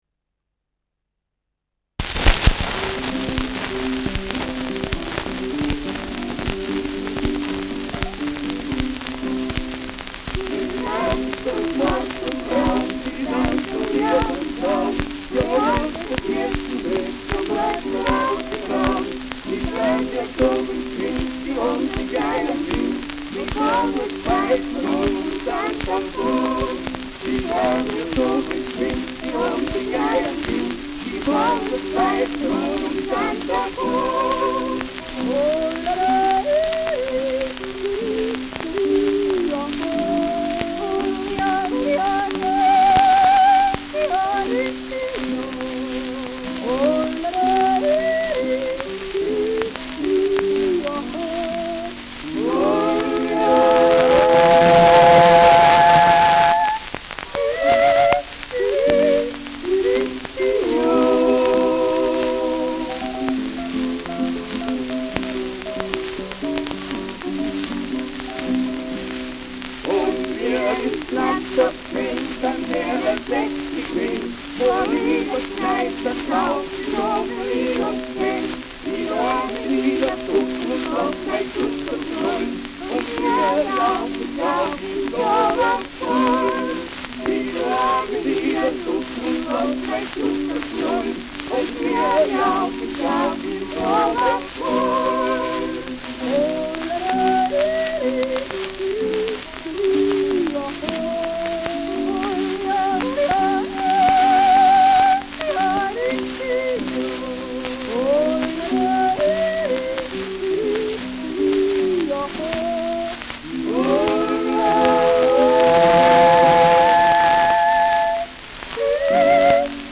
Schellackplatte
Tonrille: Kratzer 4 / 8 / 11 Uhr Stark : Berieb Durchgehend Stärker
ausgeprägtes Rauschen : Knacken
Zugspitzsänger (Interpretation)
[München] (Aufnahmeort)